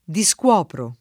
diSkU0pro]) — coniug. come coprire — ant. o poet. discovrire: discovro [